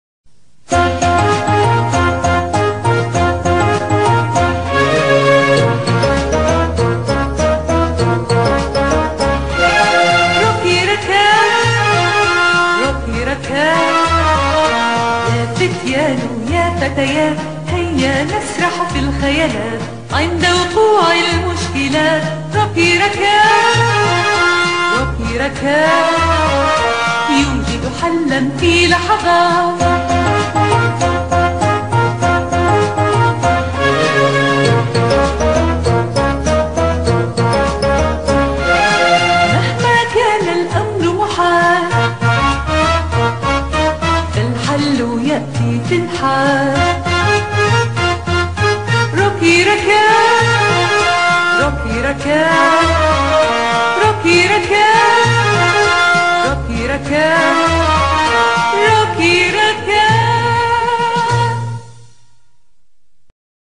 روكي راكات - الحلقة 1 مدبلجة